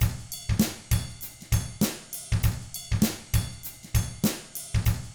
99FUNKY4T3-R.wav